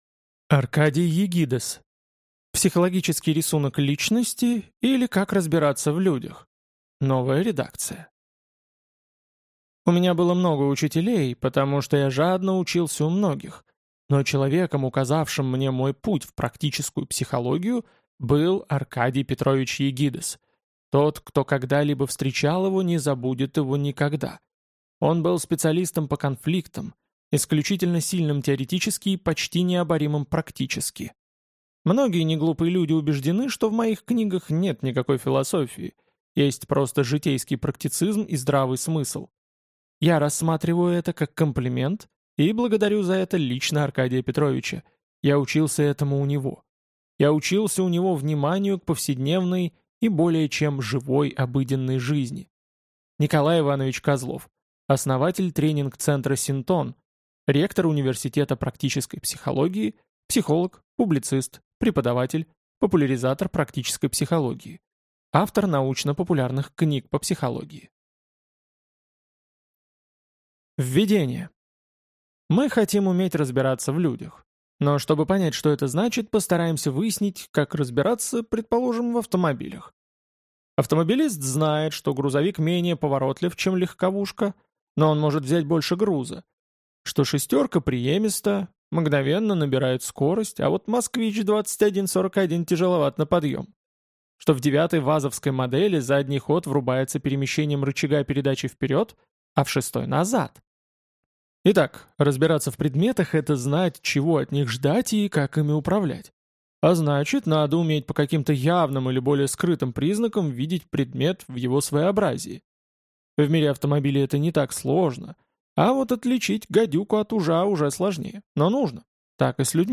Аудиокнига Психологический рисунок личности, или Как разбираться в людях | Библиотека аудиокниг